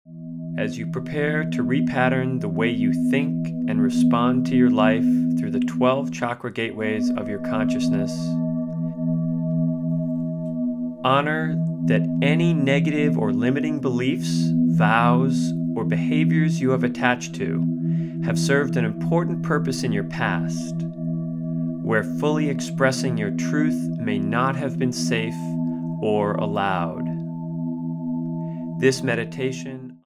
This guided meditation is the support you need to be free from the old and open to the new.